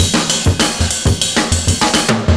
100CYMB11.wav